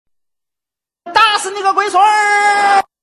岳云鹏说打死你个龟孙音效免费音频素材下载